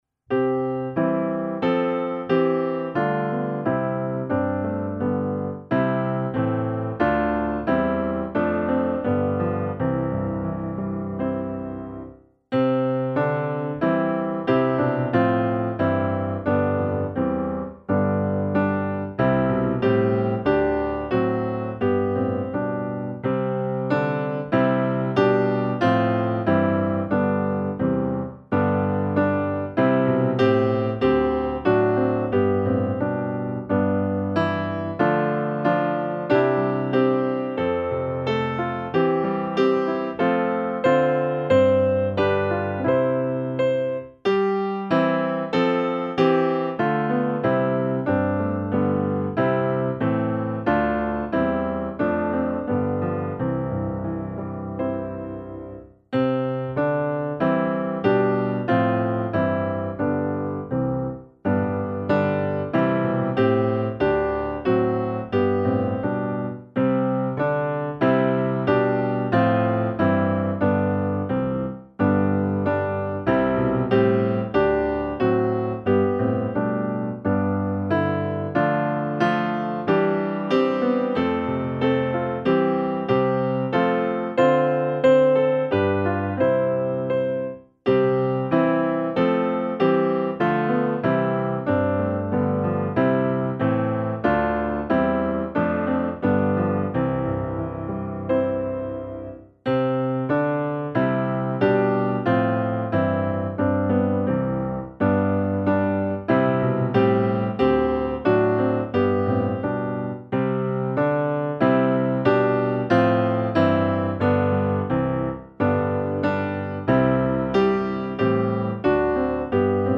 Glad jag städse vill bekänna - musikbakgrund